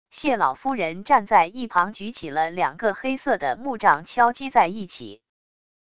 谢老夫人站在一旁举起了两个黑色的木杖敲击在一起wav音频